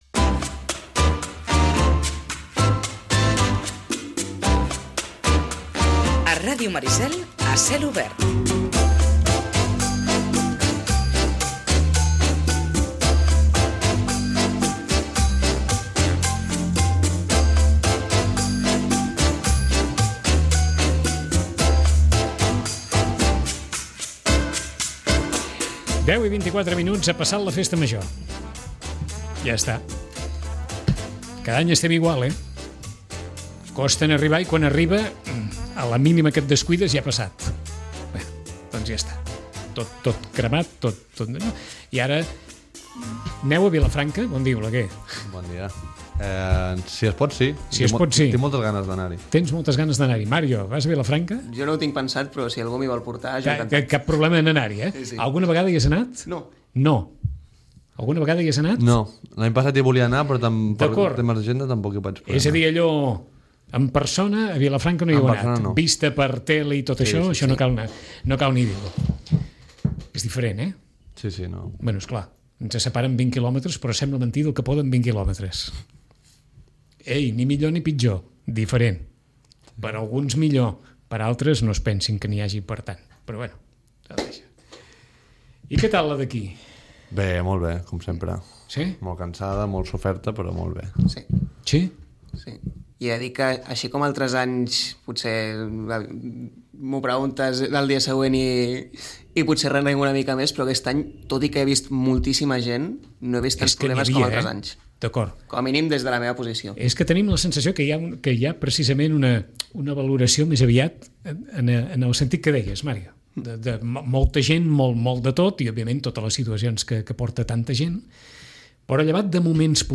Tertúlia jove: balanç de Festa Major